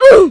OOF.ogg